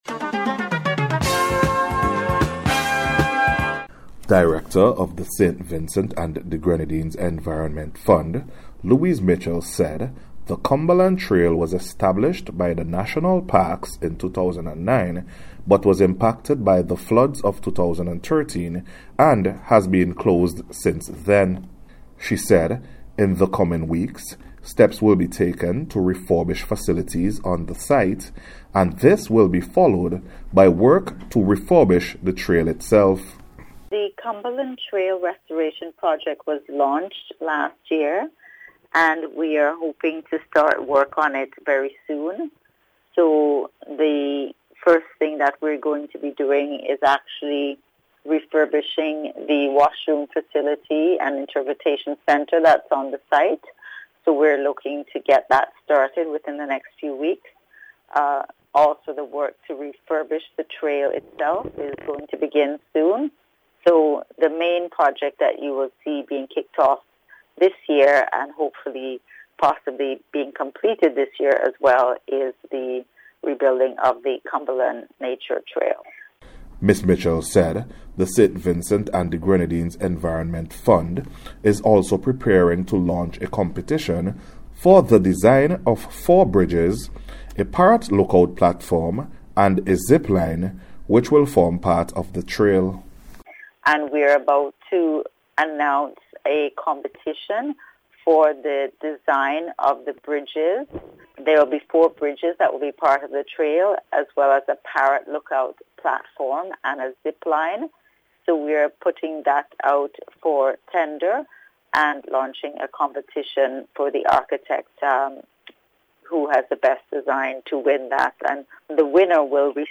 SVGEF-CUMBERLAND-TRAIL-WORK-REPORT.mp3